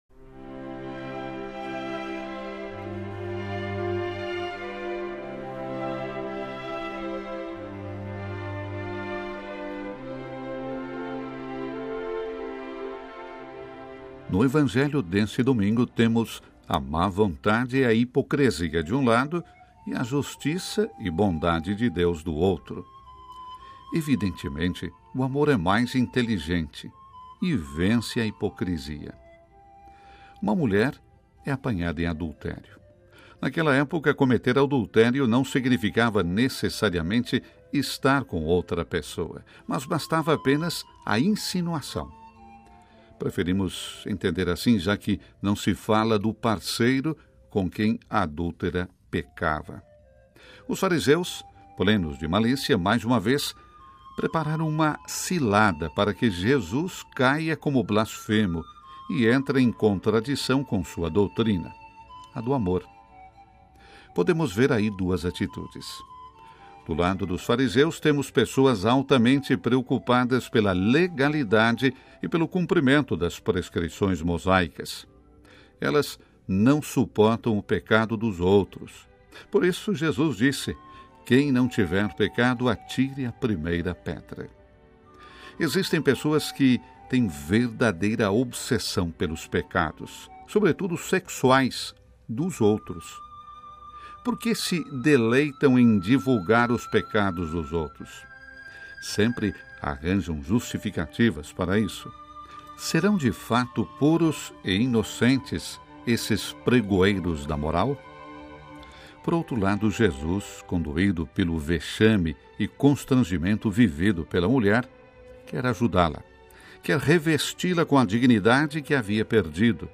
Reflexão para o V Domingo da Quaresma, 06 de abril de 2025 – Vatican News